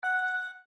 ring.mp3.svn-base